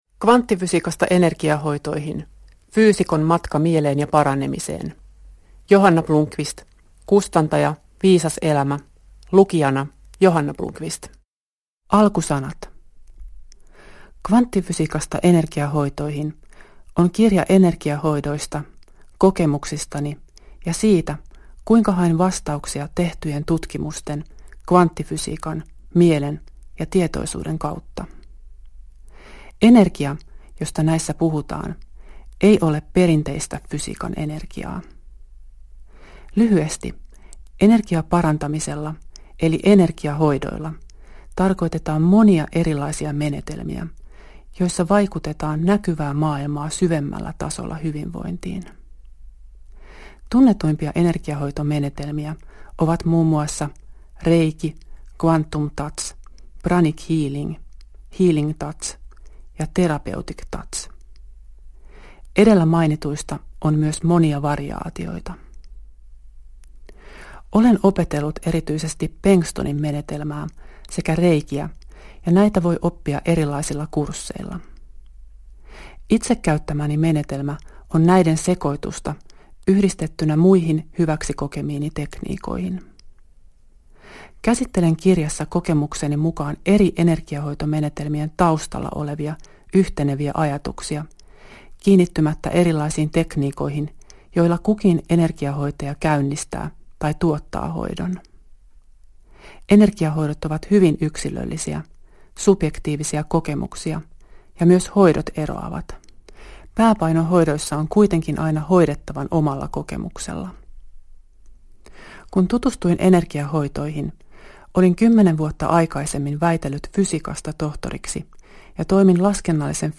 Kvanttifysiikasta energiahoitoihin – Ljudbok – Laddas ner